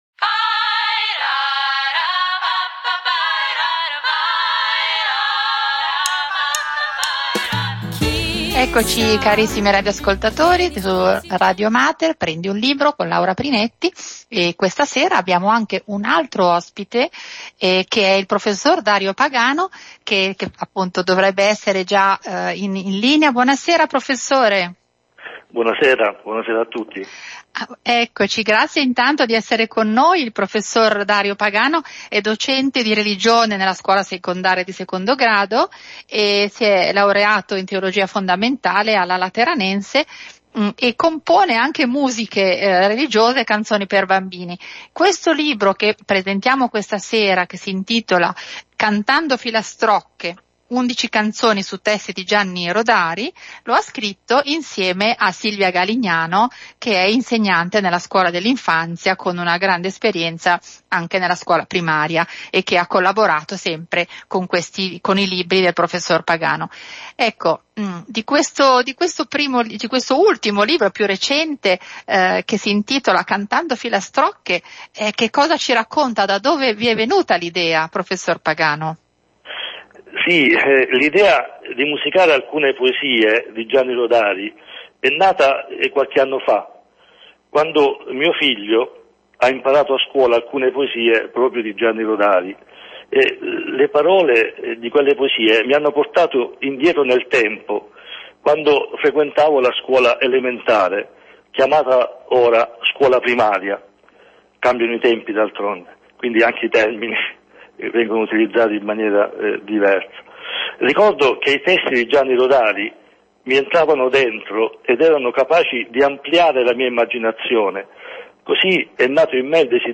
Radio Mater - PRENDI UN LIBRO - intervista